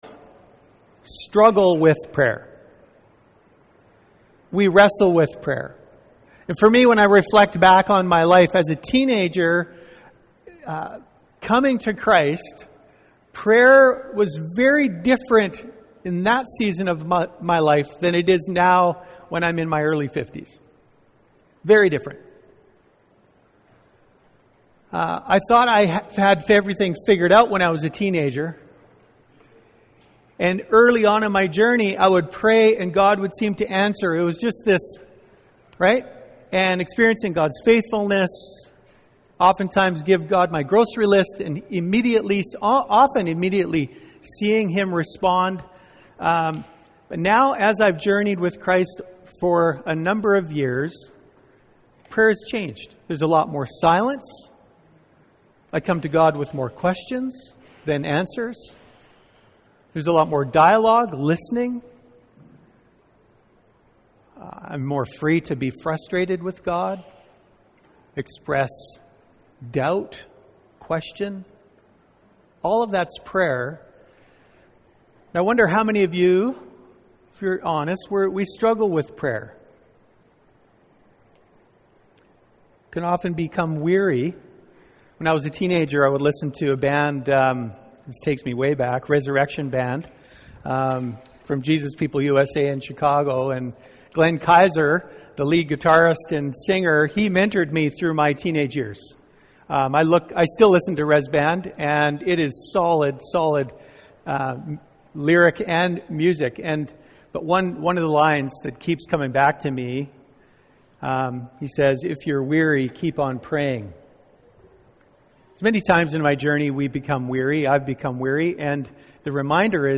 Sermons | The River Church